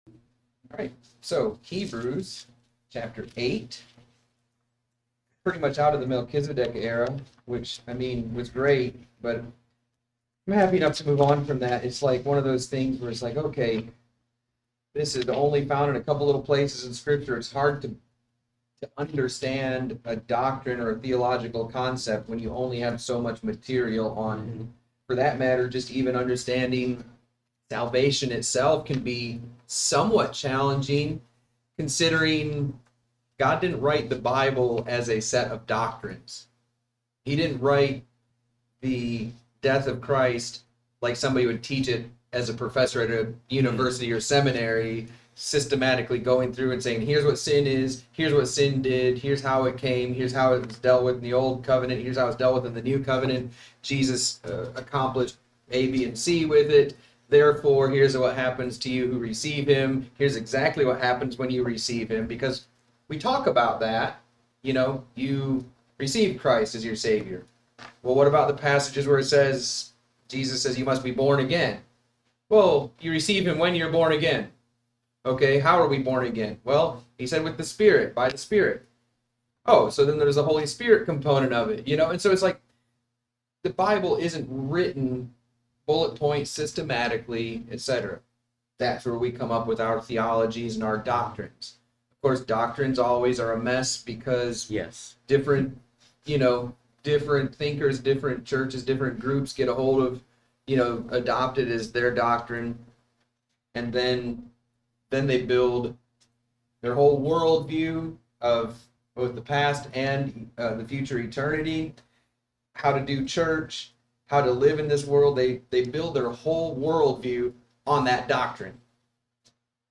Great discussion from our class today!